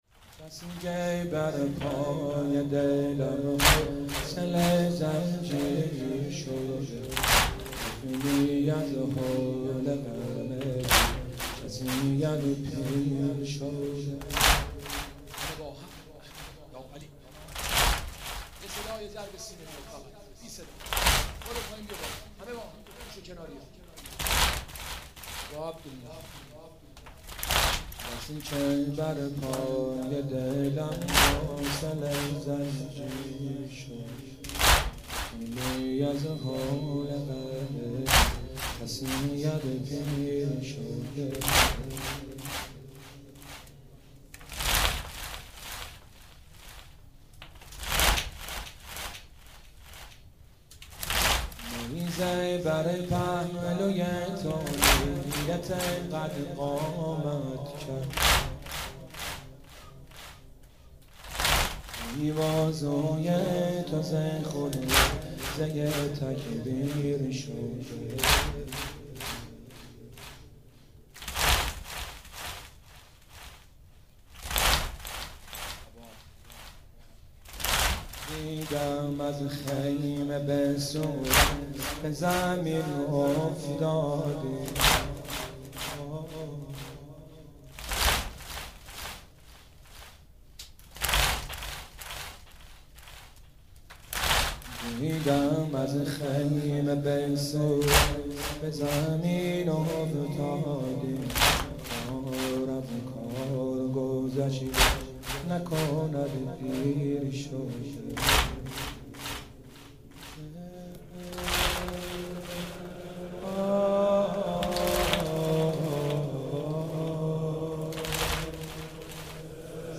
محرم96 - واحد - بسکه بر پای دلم حوصله زنجیر شده